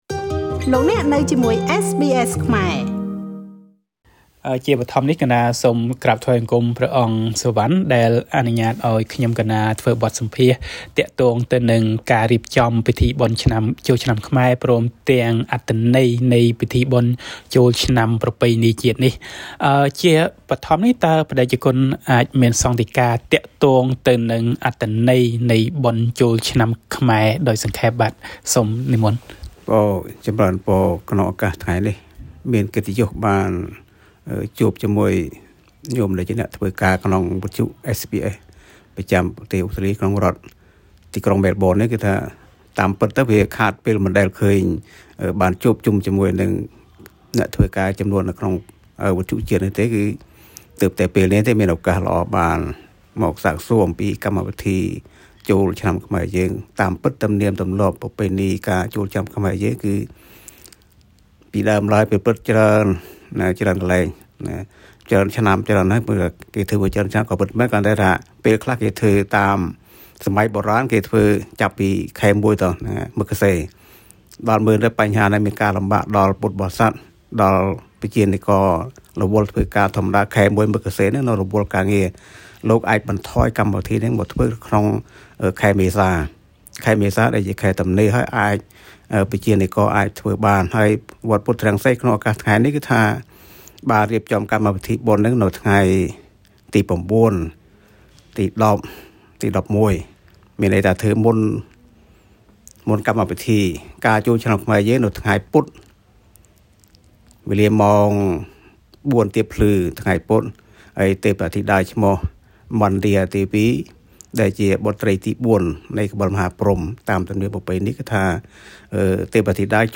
បទសម្ភាសន៍ស្តីពីការរៀបចំពិធីបុណ្យចូលឆ្នាំថ្មីតាមបណ្តាវត្តនៅទីក្រុងម៉ែលប៊ិន